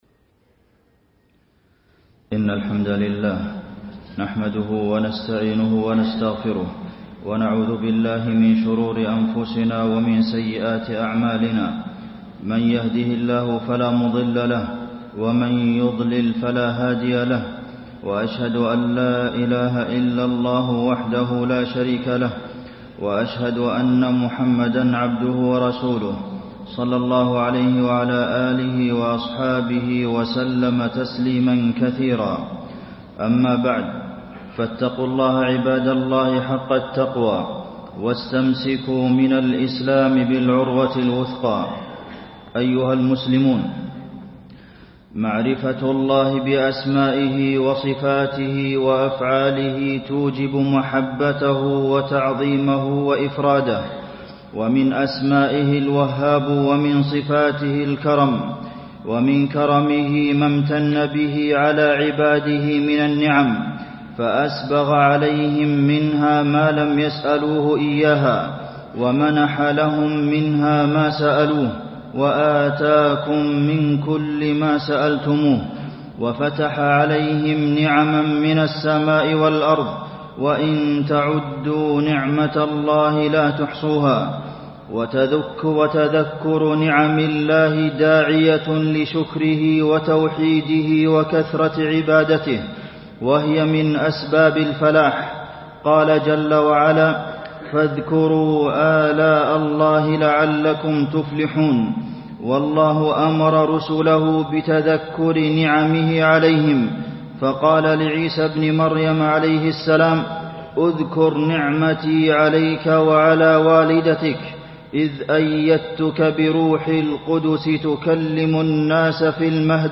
تاريخ النشر ١٣ جمادى الآخرة ١٤٣٣ هـ المكان: المسجد النبوي الشيخ: فضيلة الشيخ د. عبدالمحسن بن محمد القاسم فضيلة الشيخ د. عبدالمحسن بن محمد القاسم وجوب شكر النعم